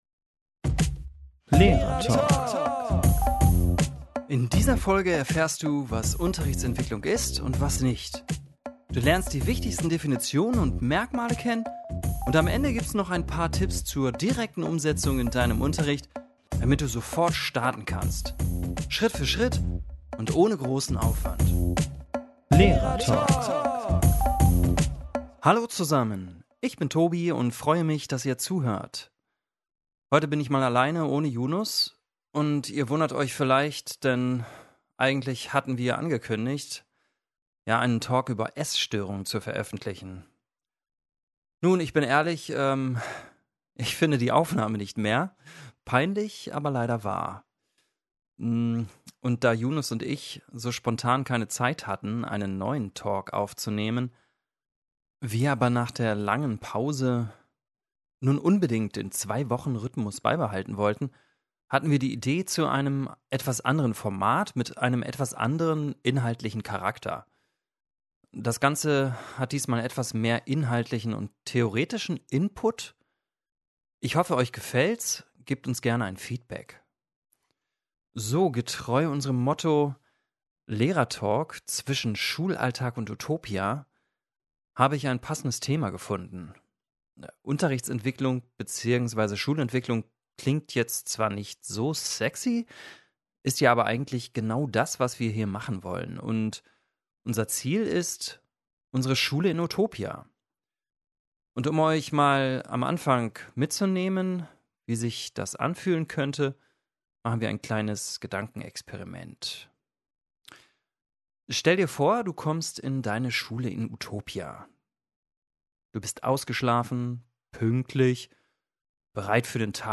Achtung: NEUES FORMAT aus der Not entstanden: Input statt Gespräch. In dieser Folge dreht sich alles um Unterrichtsentwicklung: Ich erkläre, was genau dahinter steckt und stelle die Definitionen von Hilbert Meyer und Hans-Günter Rolff vor.
Mit praxisnahen Beispielen, verständlich erklärt und mit einem Schuss Humor.